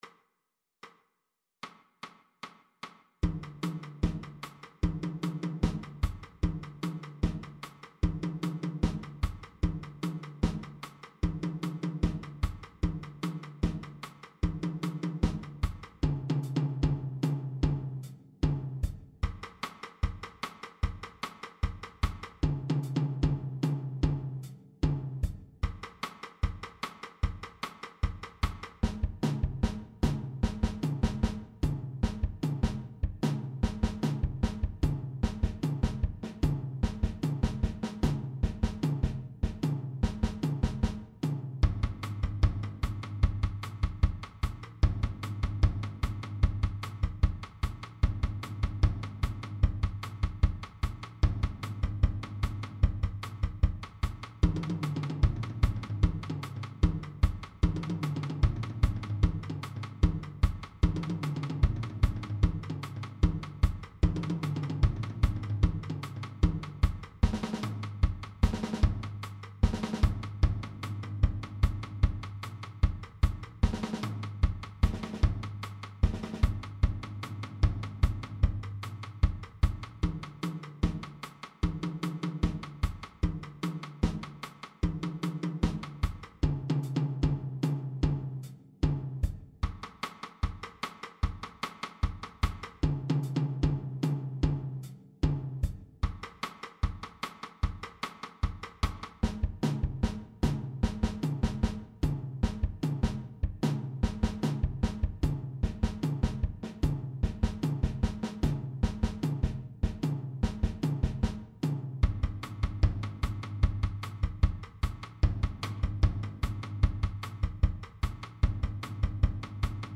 Ансамбль барабанщиков объединения
Мультитом
Тарелки
Большой Барабан
batukada-1-chast.mp3